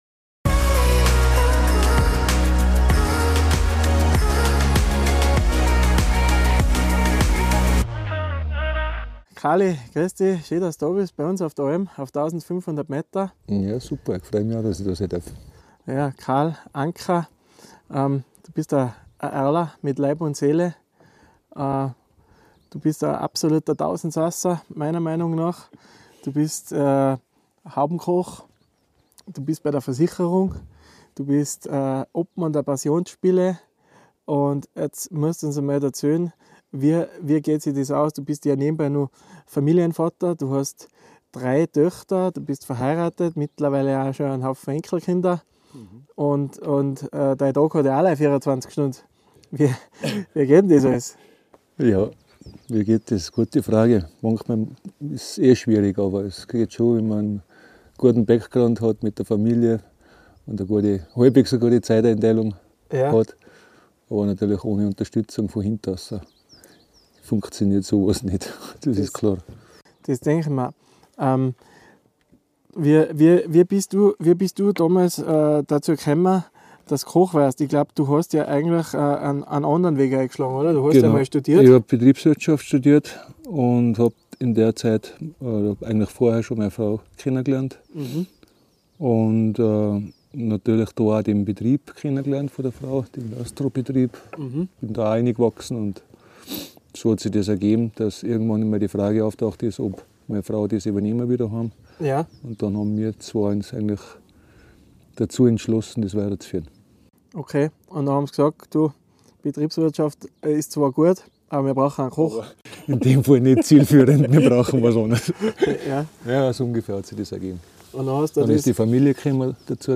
Ein ehrliches Gespräch über Leidenschaft, Disziplin und Heimatverbundenheit